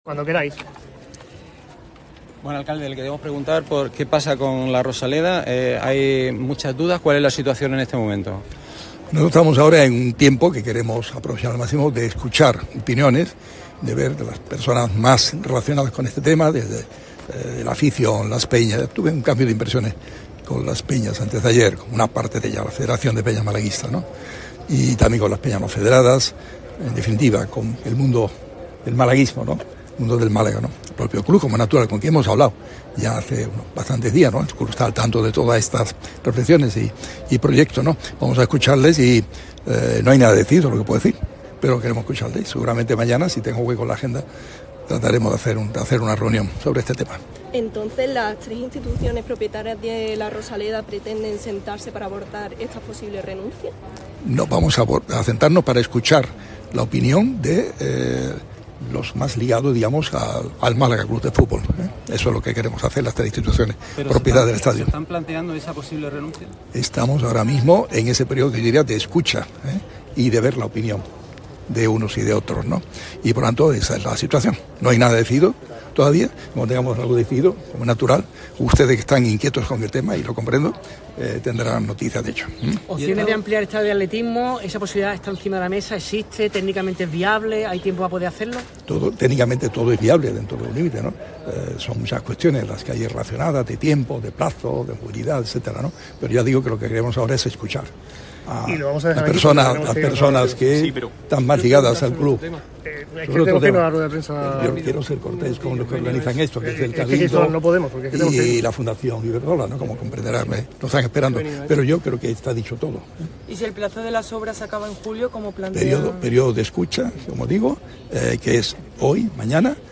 Nueva declaración del alcalde de Málaga en cuanto a toda la polémica generada con la sede mundialista para 2030, las obras de La Rosaleda que hay que llevar a cabo para ello y el traslado del Málaga CF al estadio Ciudad de Málaga. En un acto oficial, el alcalde ha respondido a distintas cuestiones sobre las dudas actuales, una posible renuncia, como va el tema de los tiempos y el cruce de declaraciones con el ministerio.